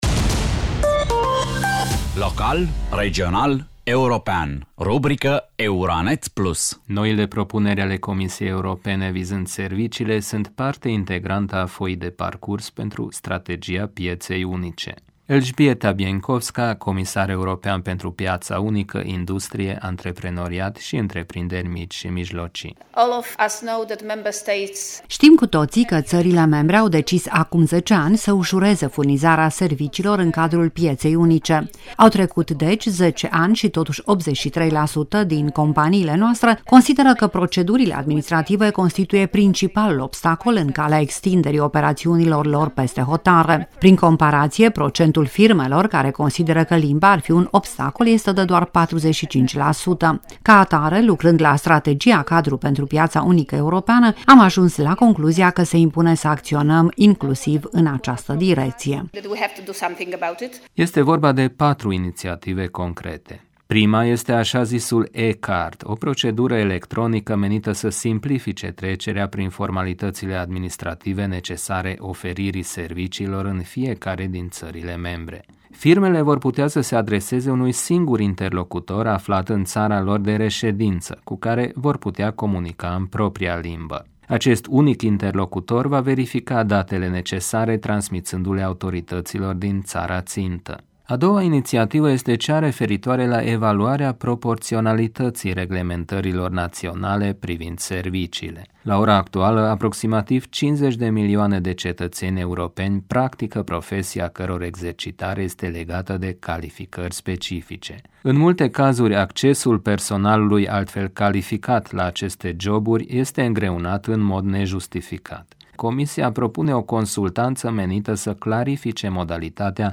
Elżbieta Bieńkowska, comisar european pentru Piaţa Unică, Industrie, Antreprenoriat şi Întreprinderi Mici şi Mijlocii: